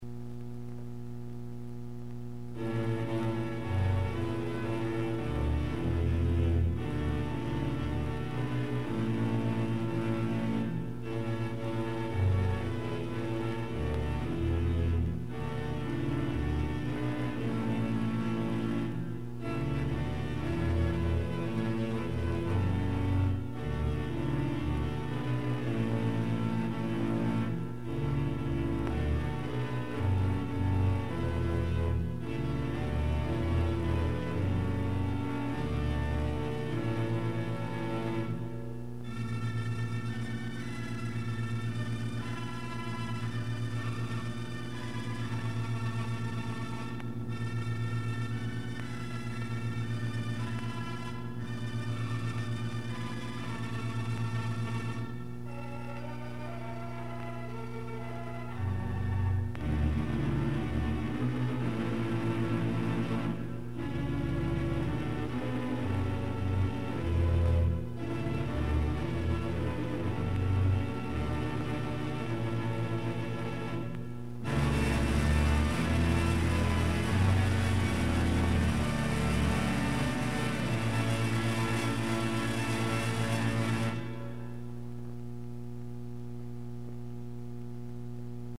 Pipe Organ Music